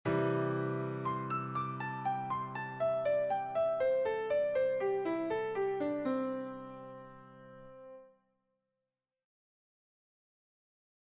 Improvisation Piano Jazz
Descendant